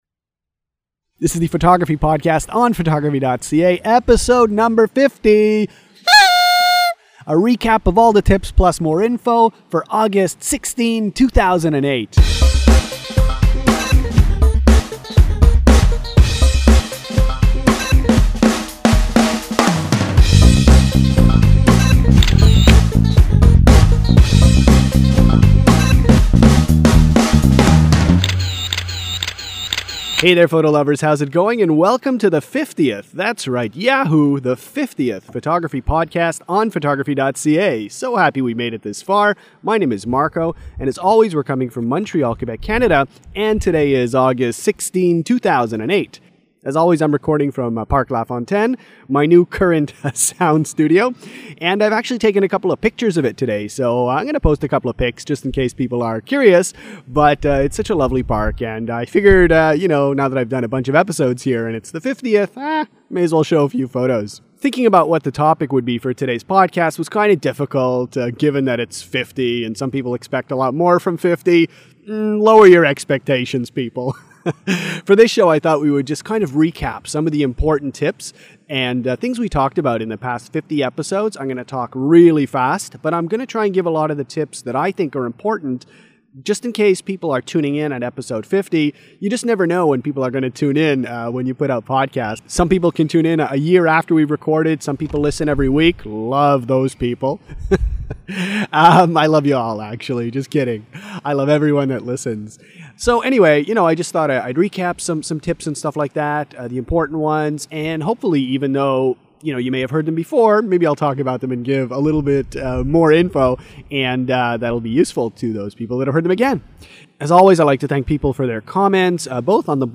Interview
Here’s a quick snapshot of Parc-Lafontaine where a little visitor came to say hi while I was recording the intro and conclusion to this podcast. That’s right, my outdoor sound studio includes wildlife